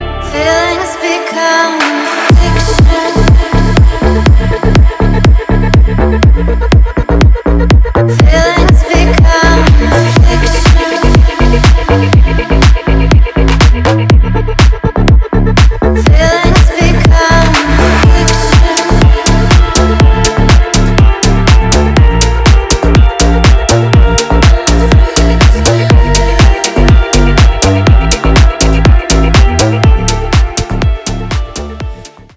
чувственные , Deep House
electronic